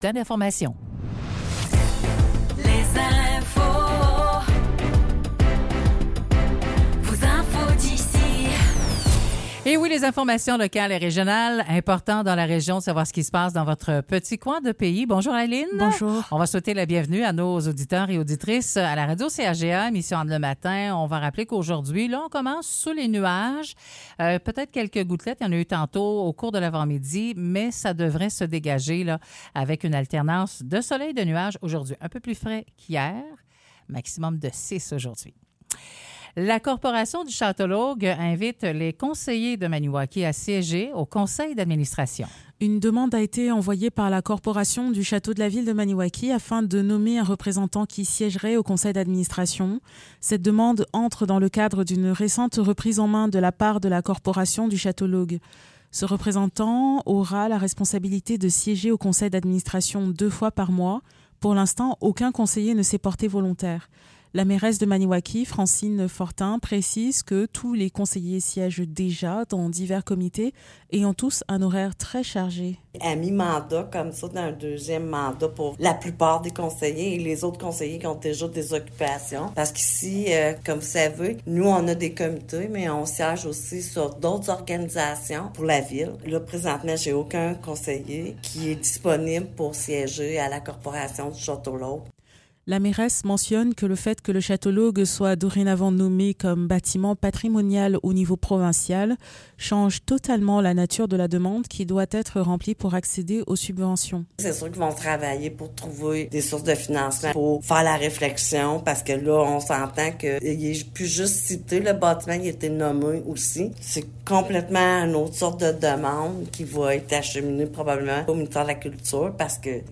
Nouvelles locales - 6 mars 2024 - 9 h